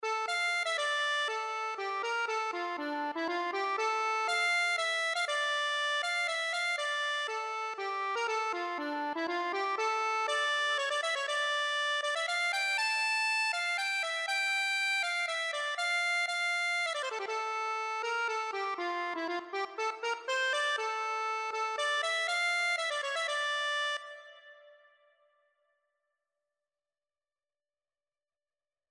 Accordion version
3/4 (View more 3/4 Music)
D5-A6
D minor (Sounding Pitch) (View more D minor Music for Accordion )
Accordion  (View more Easy Accordion Music)
Traditional (View more Traditional Accordion Music)